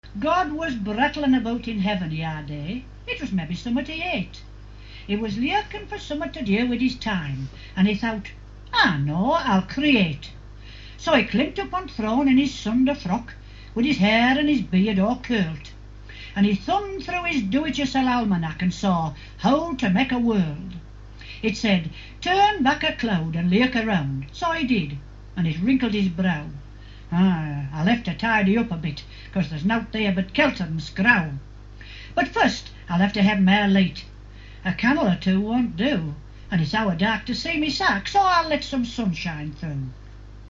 Here’s a recording in a dialect of English.
It’s somewhat Scottish, but with a bit of Yorkshire in it too.